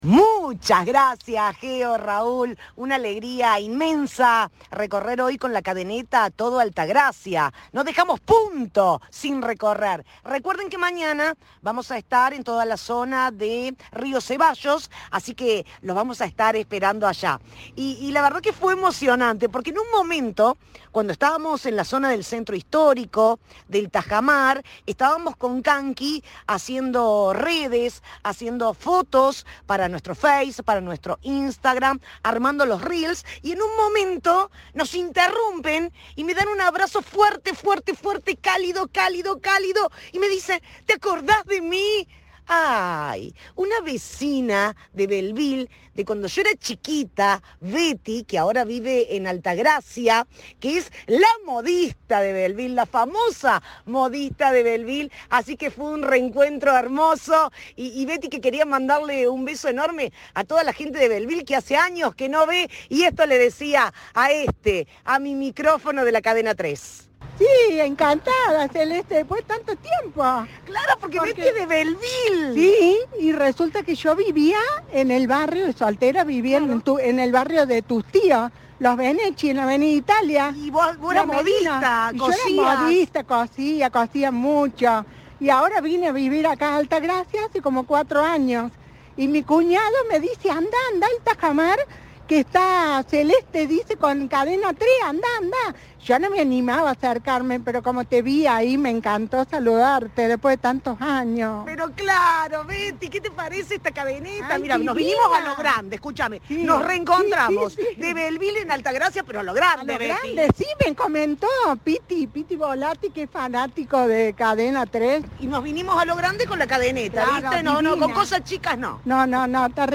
La unidad móvil recorrió los lugares típicos de la ciudad serrana como la Virgen de Lourdes, el Sierras Hotel, el Museo Manuel de Falla y el Tajamar. Los oyentes se acercaron a saludar y demostraron el vínculo con la radio federal.
Informe